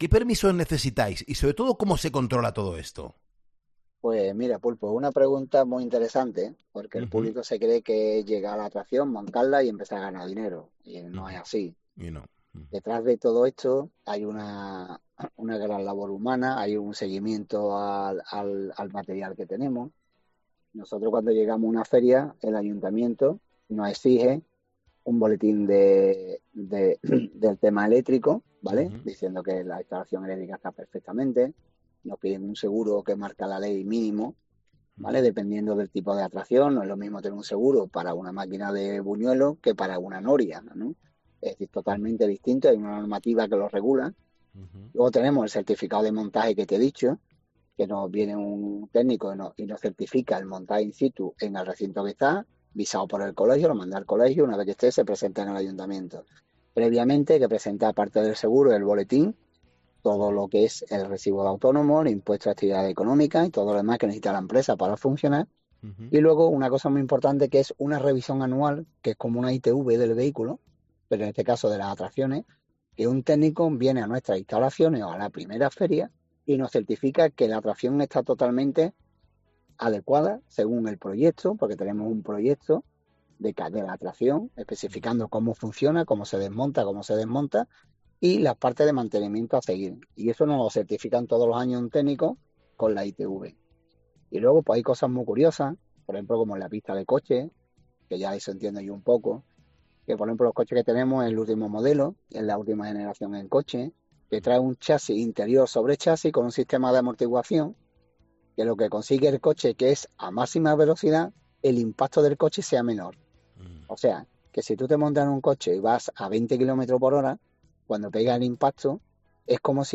Un feriante